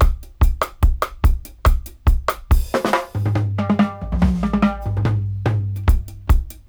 142-DRY-01.wav